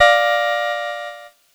Cheese Chord 28-A#4.wav